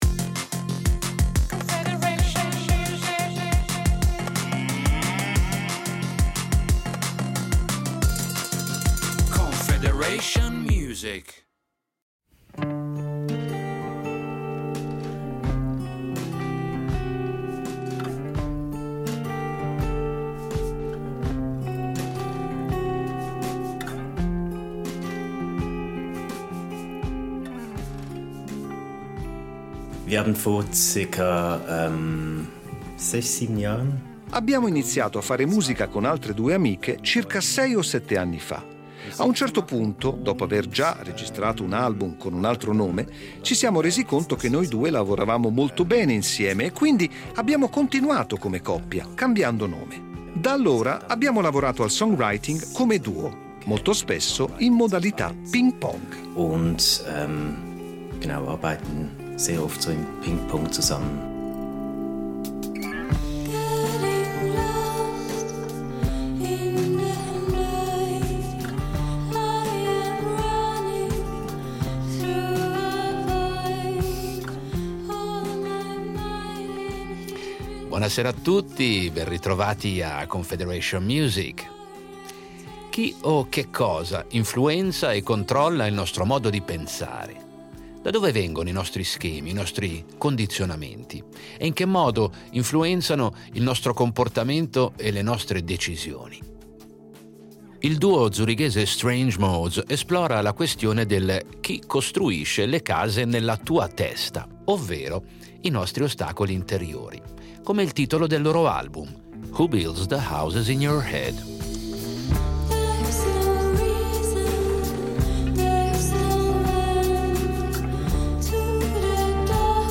Musica rock
si va dal vigore alla leggerezza, dallo stato di coscienza alterata che rasenta la psichedelia e su fino a una potenza quasi noise.